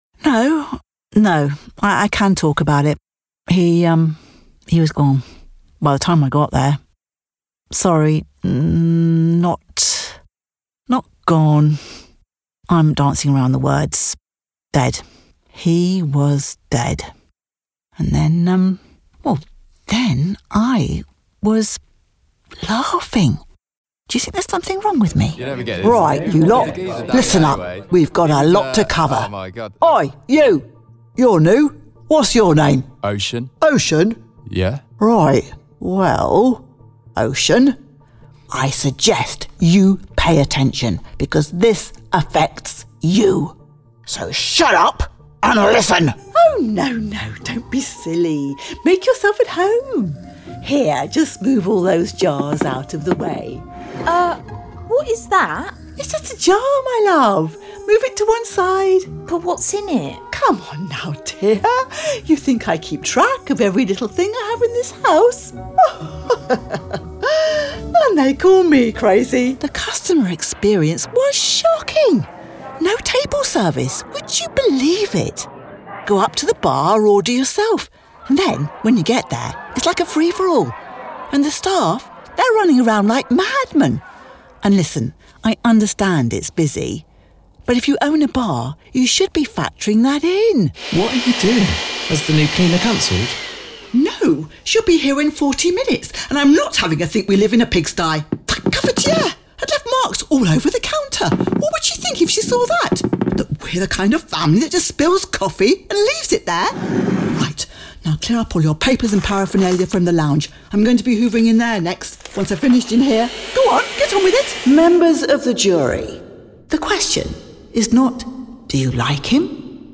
Female
English (British)
Adult (30-50), Older Sound (50+)
Character / Cartoon
Character Mix
Words that describe my voice are Approachable, Reassuring, Compassionate.